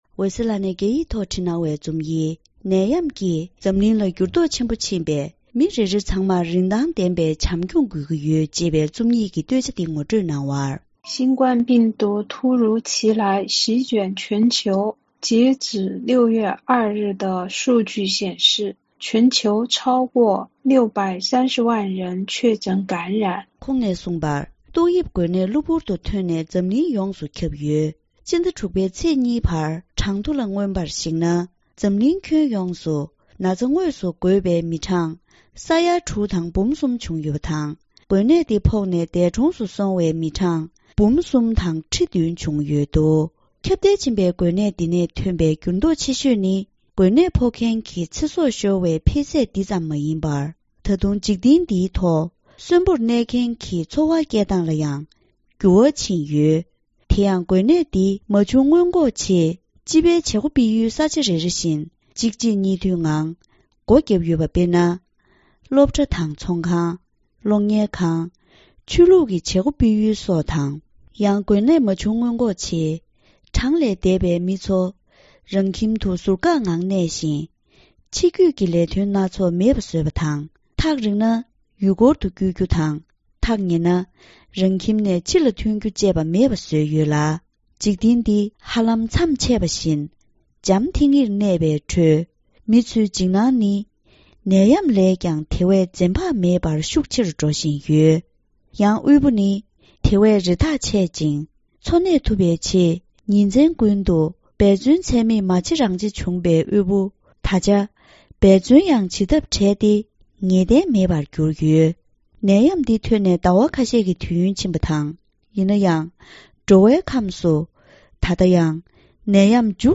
ཕབ་བསྒྱུར་སྙན་སྒྲོན་གནང་གི་རེད།